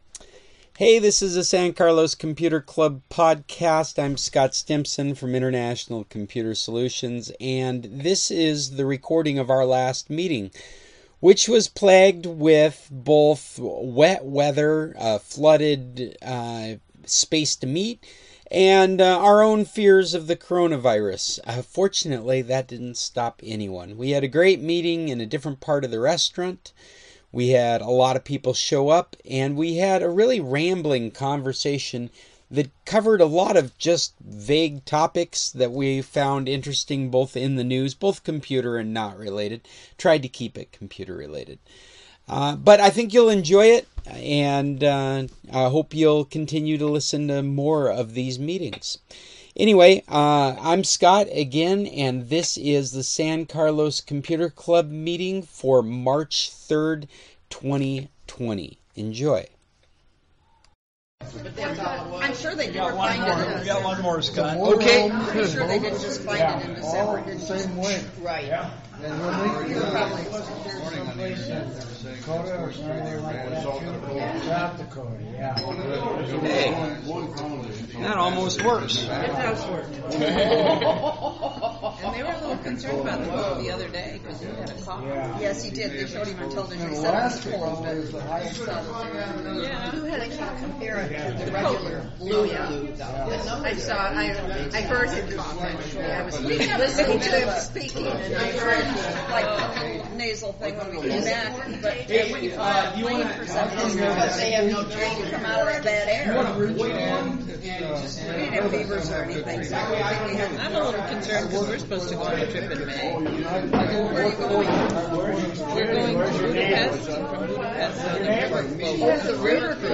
Hello to all currently surviving rain flood victims who took shelter in the other half of Tequila’s restaurant (i.e., NOT under the palapa) and braved coronavirus to be with us on this 22nd meeting of the 2019-2020 season of The San Carlos Computer Club.
If you missed our meeting in real-time, then you can always listen to this podcast.